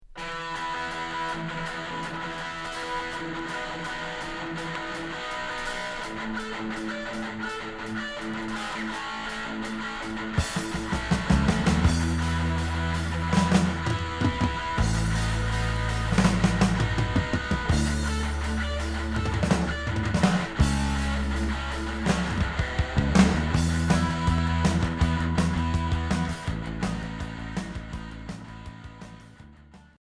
Hard